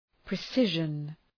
Προφορά
{prı’sıʒən}